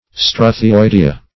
Search Result for " struthioidea" : The Collaborative International Dictionary of English v.0.48: Struthioidea \Stru`thi*oi"de*a\, n. pl.